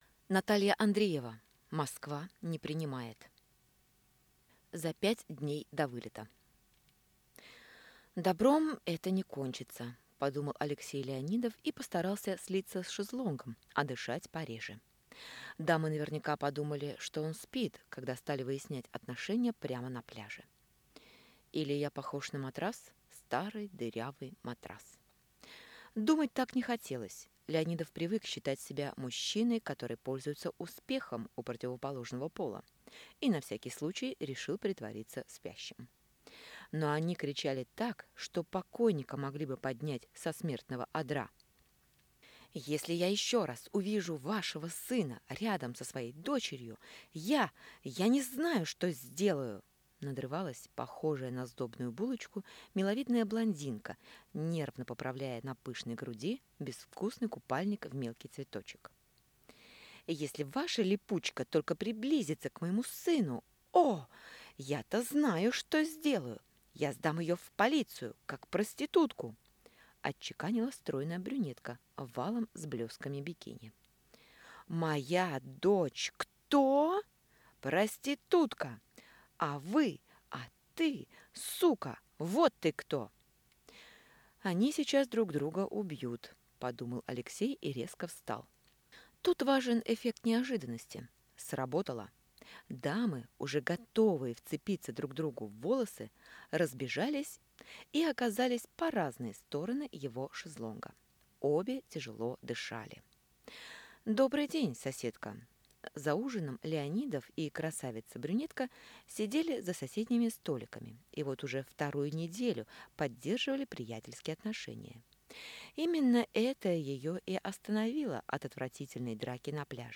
Аудиокнига Москва не принимает | Библиотека аудиокниг